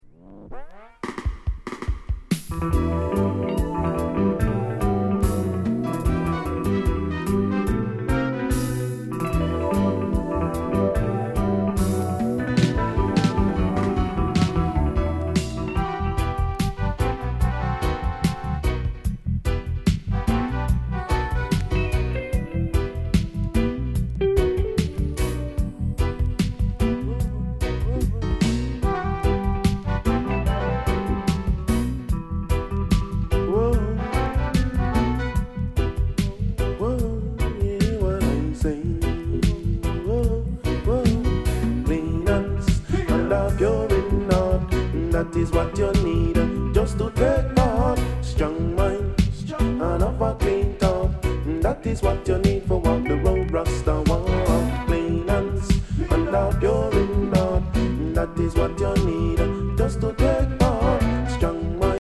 STEPPER ROOTS